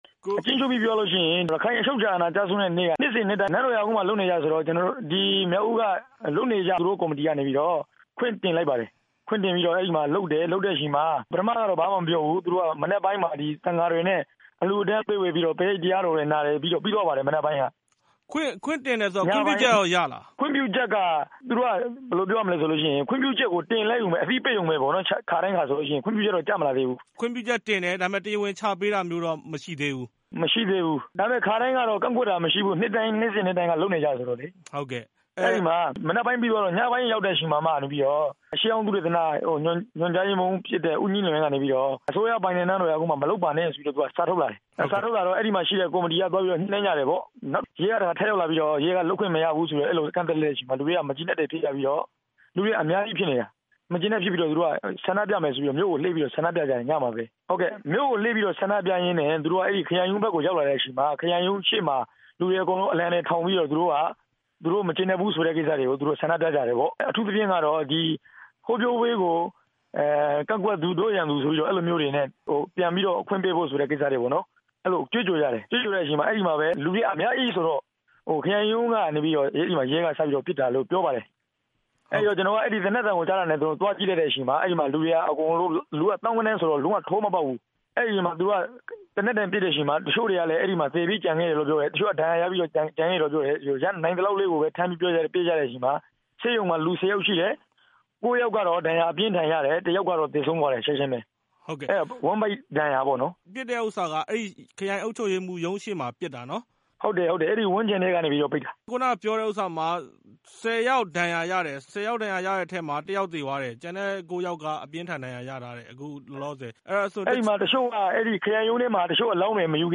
မျက်မြင်သက်သေတဦးကို အခင်းဖြစ်ပွားပြီး မကြာခင်မှာပဲ ဆက်သွယ်မေးမြန်းထားပါတယ်။